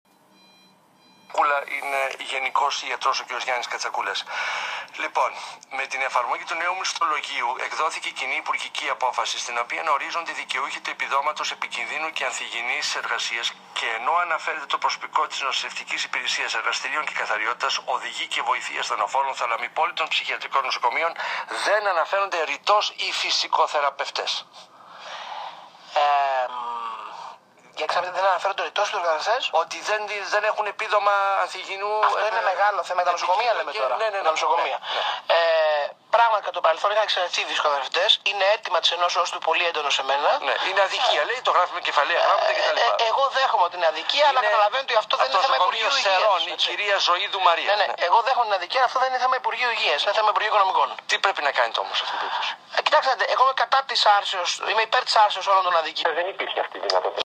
Απόσπασμα συνέντευξης Υπουργού Υγείας για το ανθυγιεινό επίδομα των φυσικοθεραπευτών - Π.Σ.Φ.